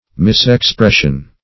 Misexpression \Mis`ex*pres"sion\, n.